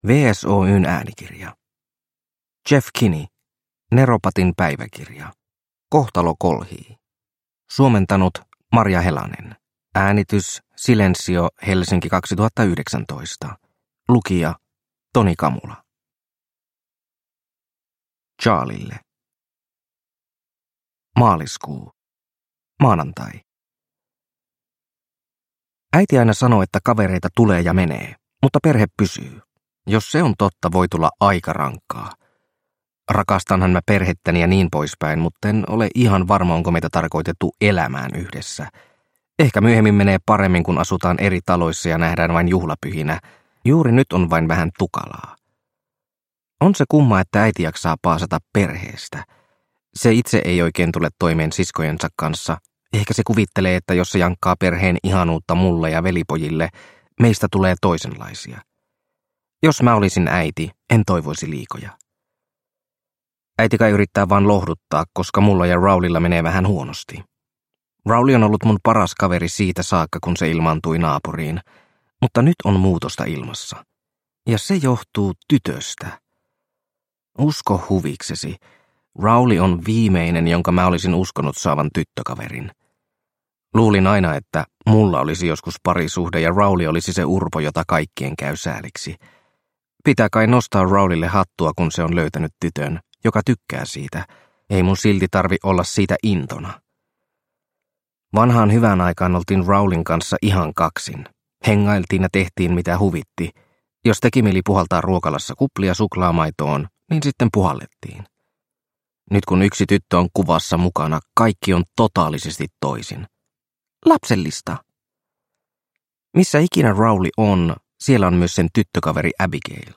Neropatin päiväkirja: Kohtalo kolhii – Ljudbok – Laddas ner